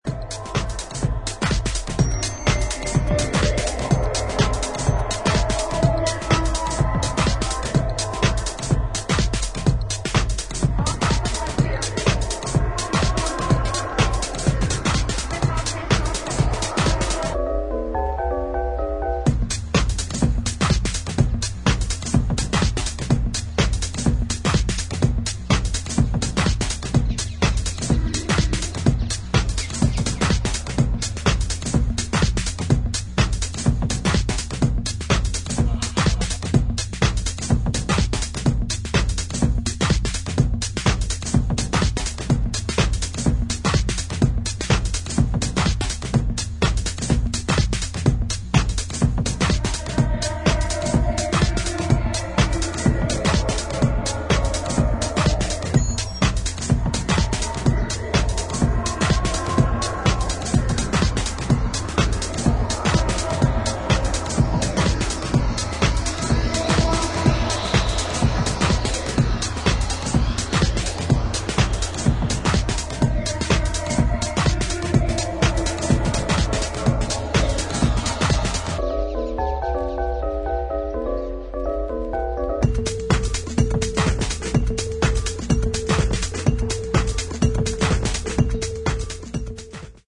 』で構成された、クールなテックハウス4曲を収録。